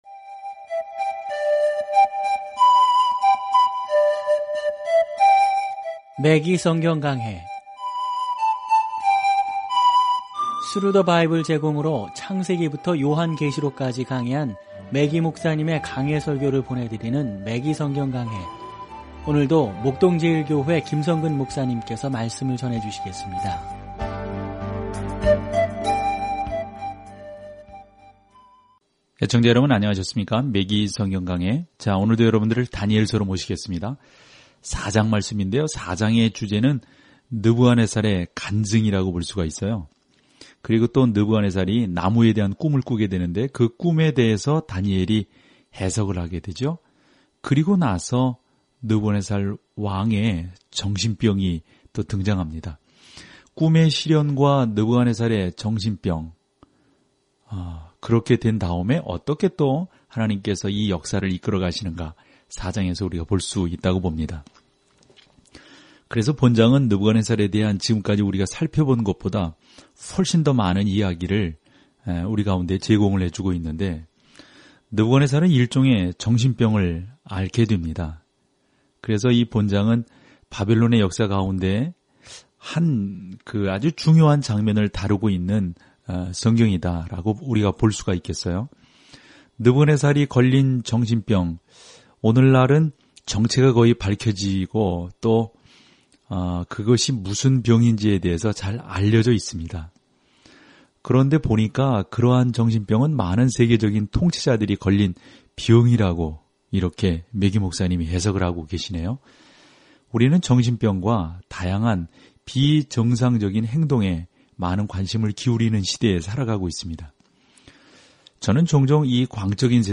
말씀 다니엘 4:1-16 8 묵상 계획 시작 10 묵상 소개 다니엘서는 하나님을 믿었던 한 사람의 전기이자, 결국 세상을 통치하게 될 사람에 대한 예언적 환상입니다. 오디오 공부를 듣고 하나님의 말씀에서 선택한 구절을 읽으면서 다니엘을 통해 매일 여행하십시오.